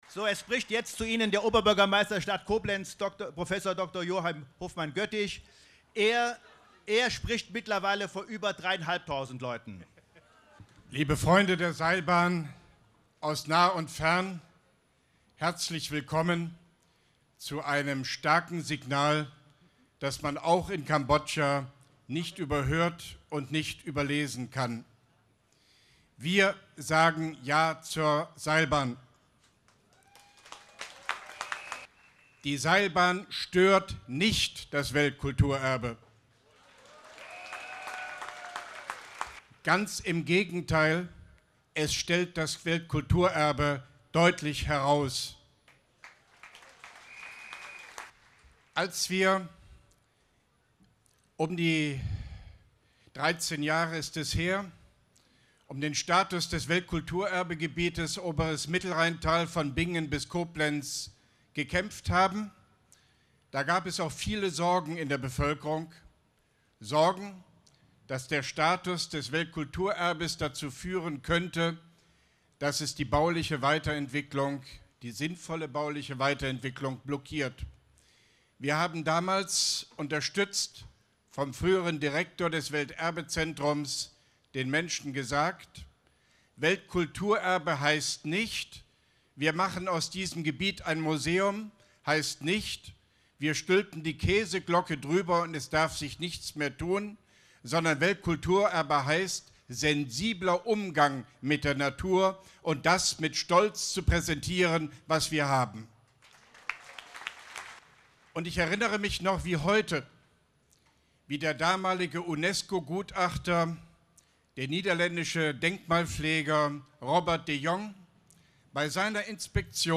Koblenz Seilbahndemo Rede Oberbürgermeister Hofmann-Göttig 14.06.2013